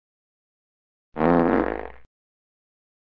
Fart Sound Button
fart-gaming-sound-effect-hd.mp3